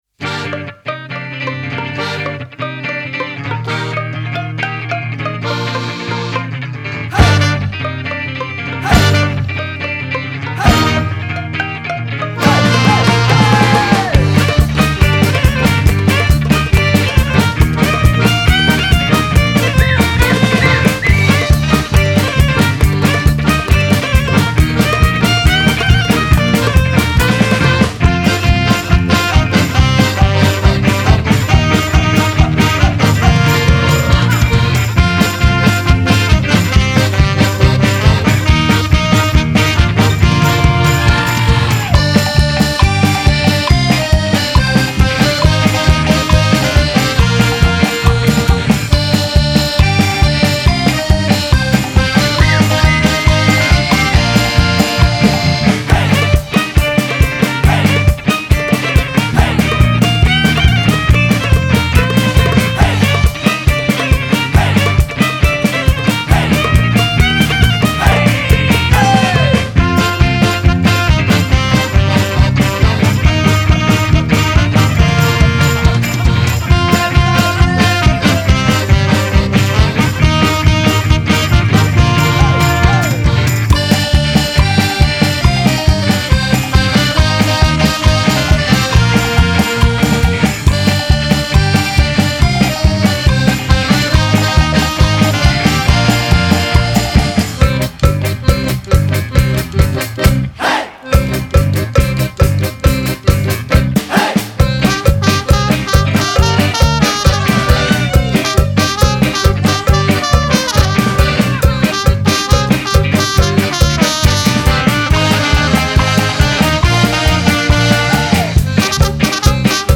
Genre: Folk-Rock, Gypsy-Punk